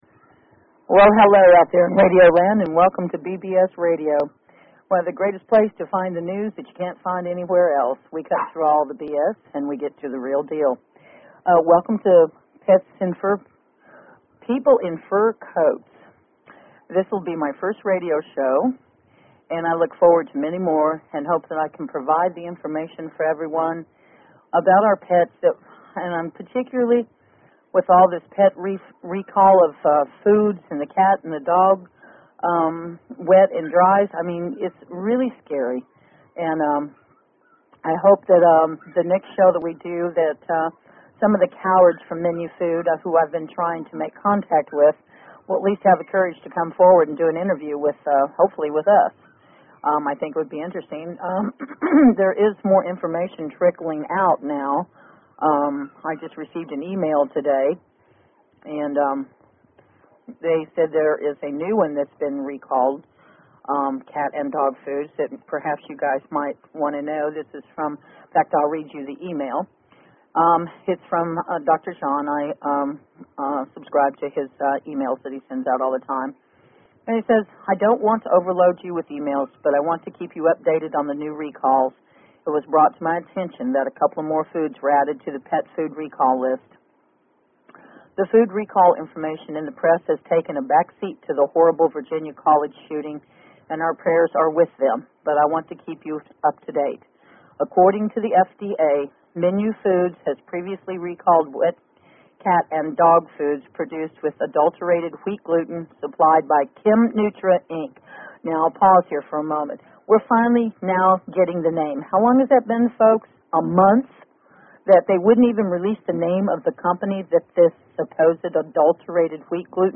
Talk Show Episode, Audio Podcast, People_in_Fur_Coats and Courtesy of BBS Radio on , show guests , about , categorized as